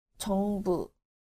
• jeongbu